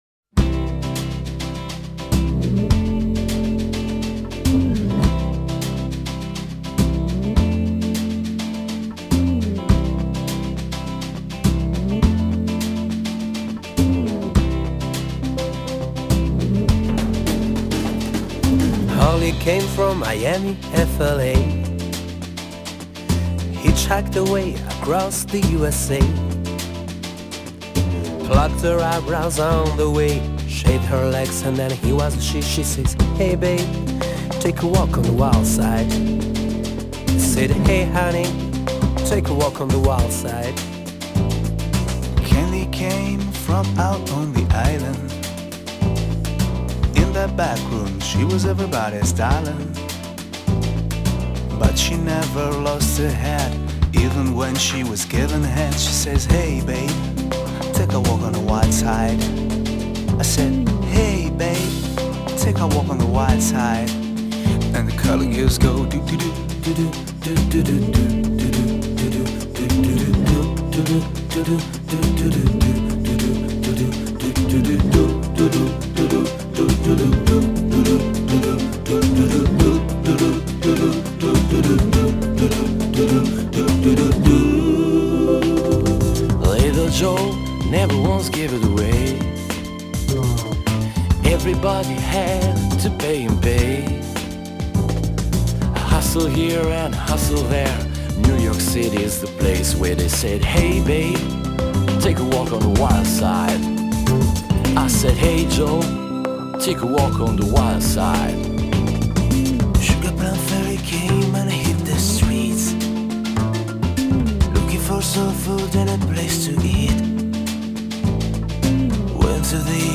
Genre: Sex Music.